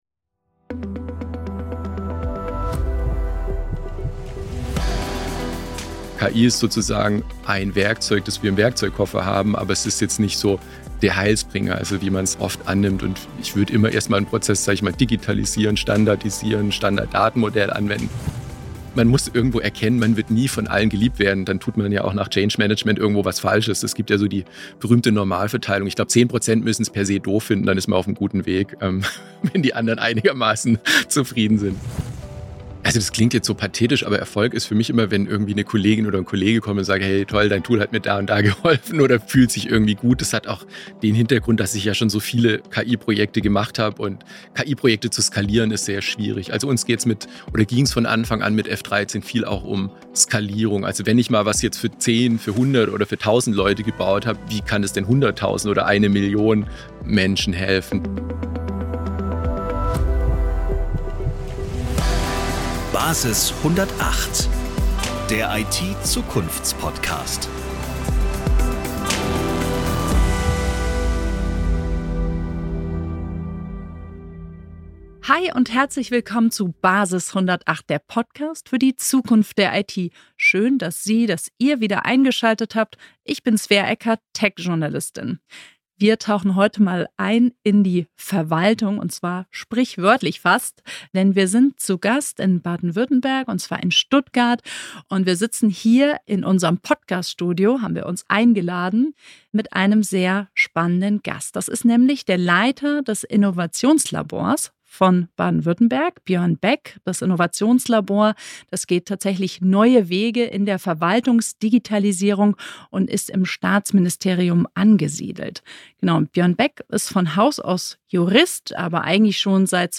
1 BSI-Präsidentin Claudia Plattner im Gespräch 35:41